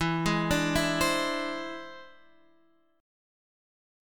E+7 chord {x 7 6 7 9 8} chord